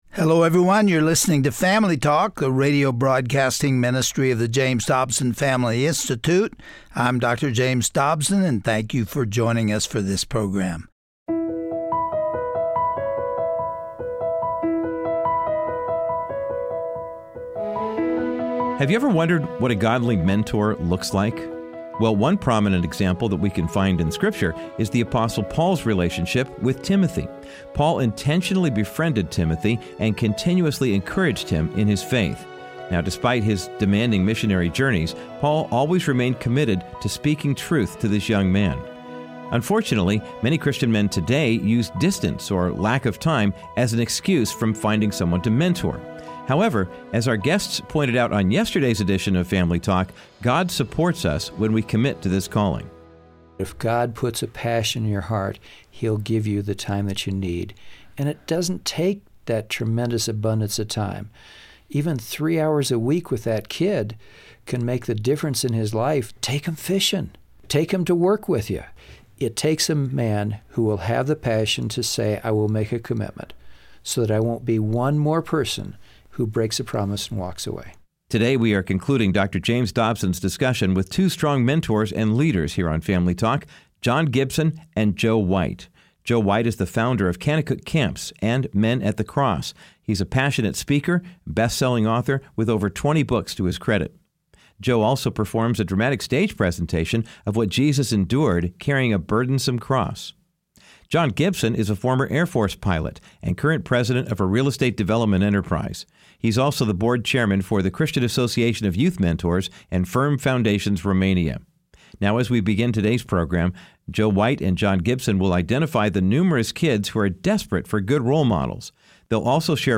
The Apostle Paul modeled healthy mentorship through his relationship with Timothy. On todays Family Talk broadcast, you will hear why his example is one that Christian men should continue to follow.